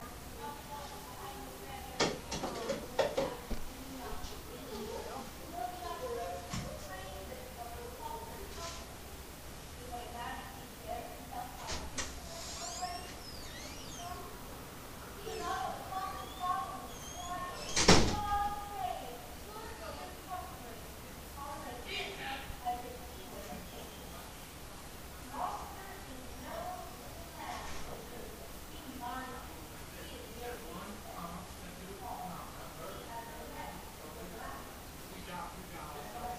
Field Recording 6
Adams Playhouse, women’s dressing room #1, 3/10/11 9:30(ish) p.m.
Sounds heard: Wars of the Roses over intercom, bottle being thrown away, but missing the garbage can, shuffling, squeaky door opening and closing